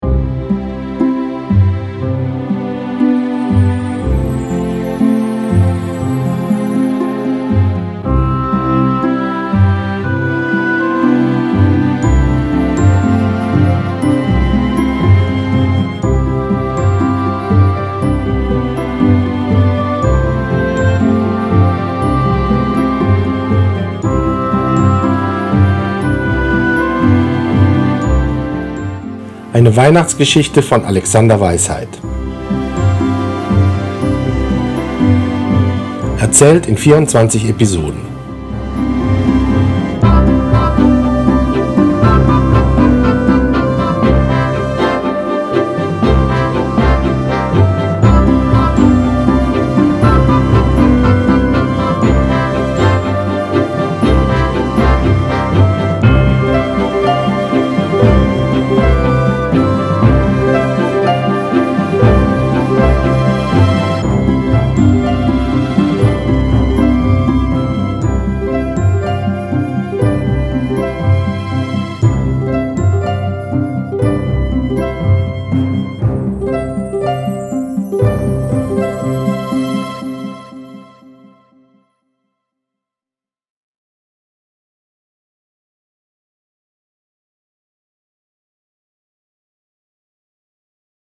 Das Intro meines Weihnachtsgeschichten-Hörspieles.